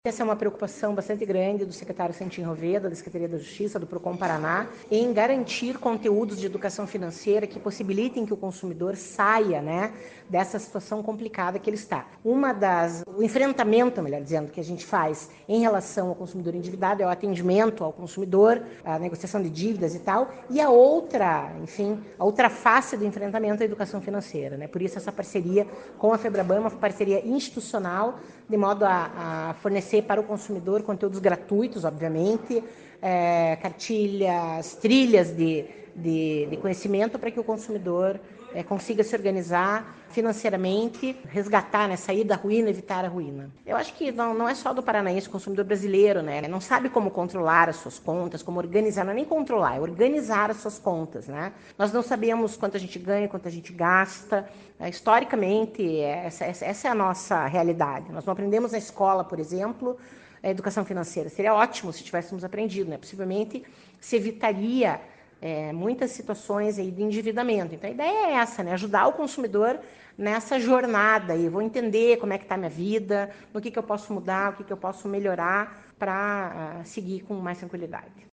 Sonora da coordenadora do Procon-PR, Claudia Silvano, sobre o uso da plataforma ‘Meu Bolso em Dia’ no Paraná